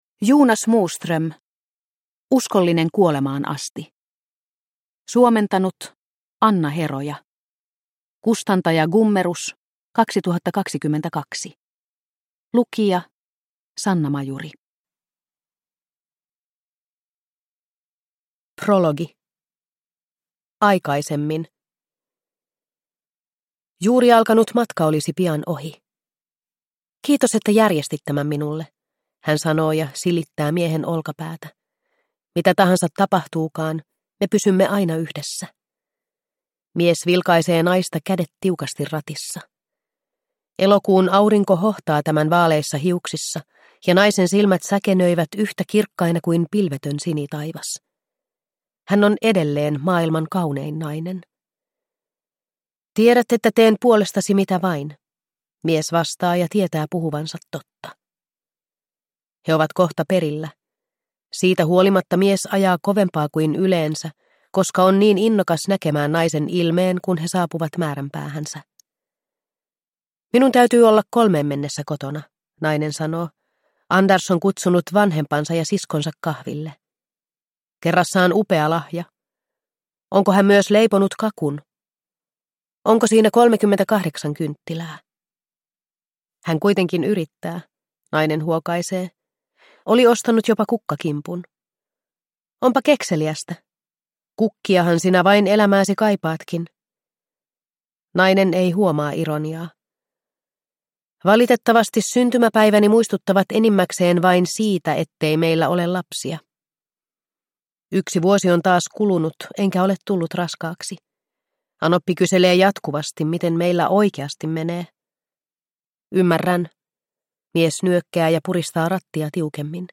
Uskollinen kuolemaan asti – Ljudbok – Laddas ner